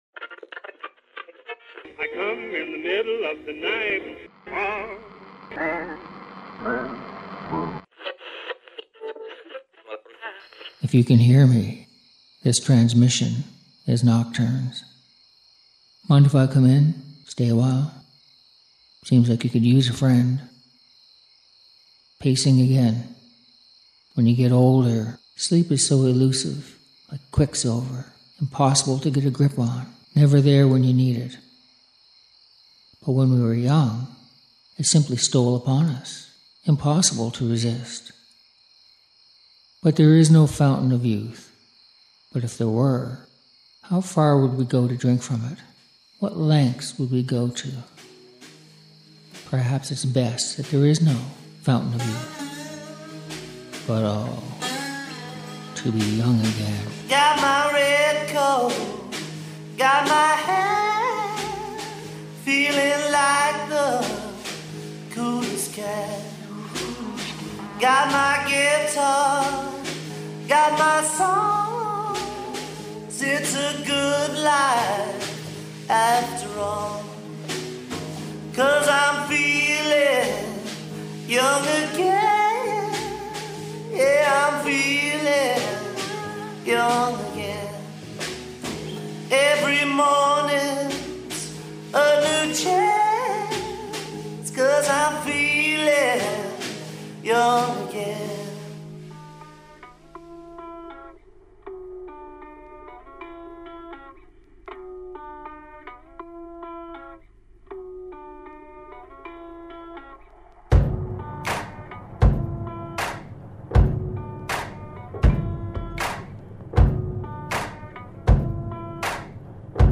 Music for nighttime listening.